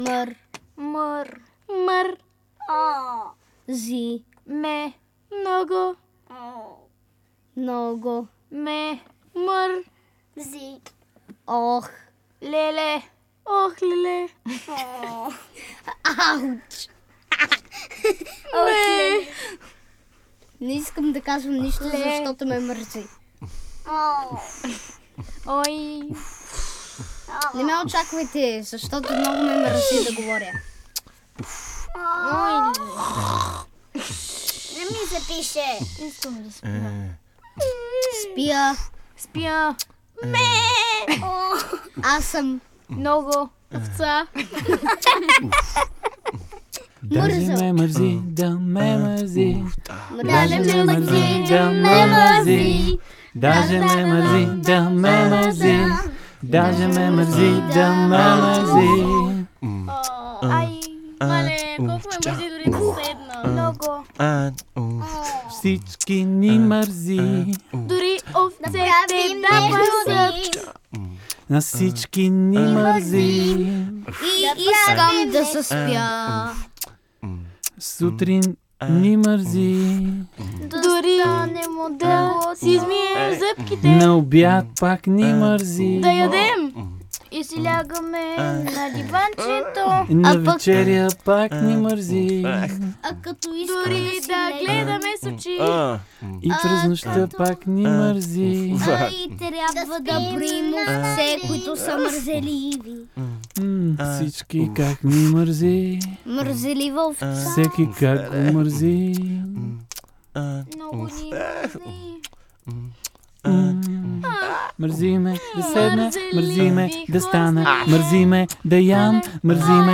Записахме албум с песни, изцяло създадени от деца.
Заедно създадохме наша импровизационна вокална група.
Всички песни са записани, смесени и мастерирани